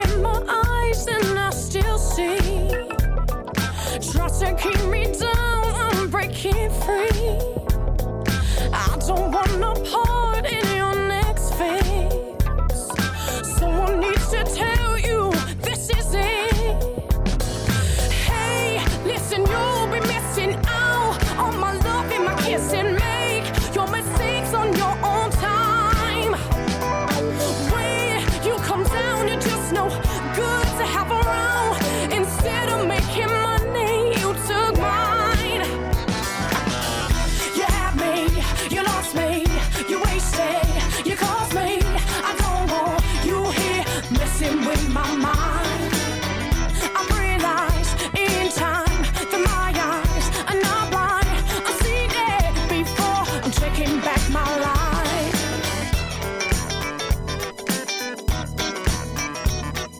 британской соул-исполнительницы